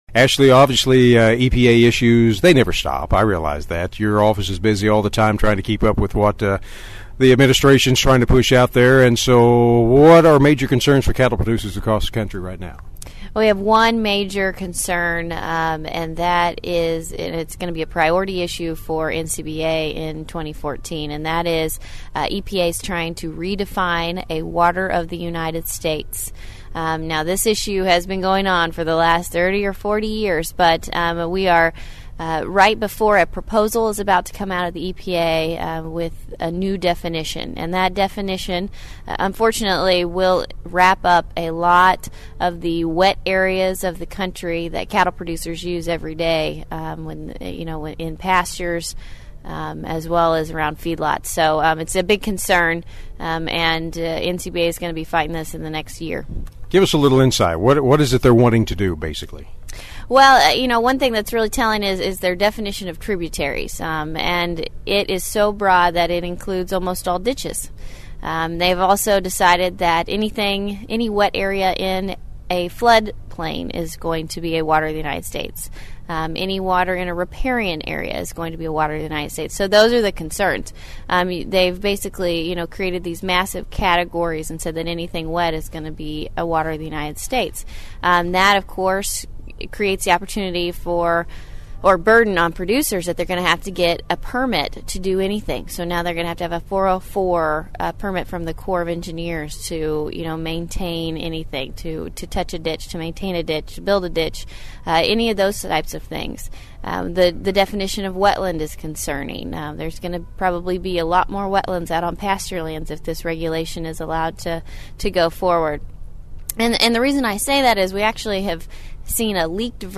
Today is the last day of the 2014 Cattle Industry Convention in Nashville, Tennessee where many topics have been discussed this week, including some taking place in our nation’s capitol.